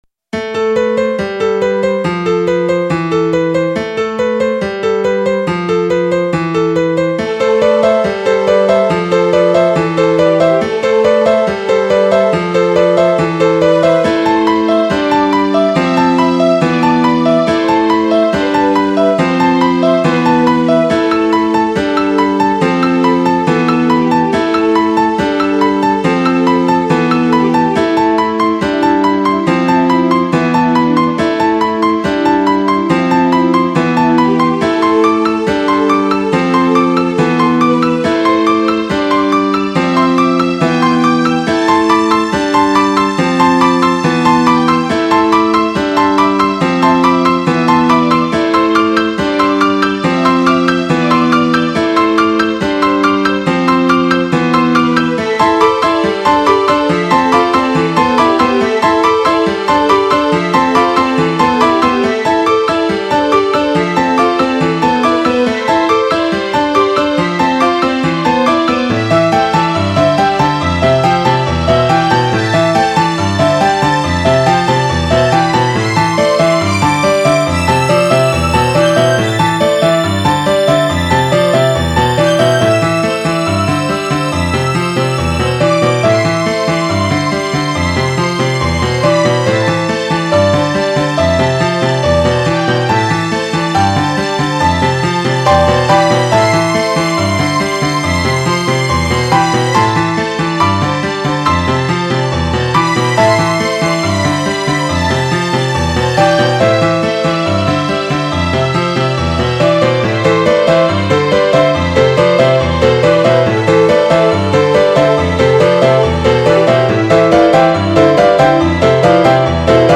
そして寒い冬がやってくる、切ない感じの曲です。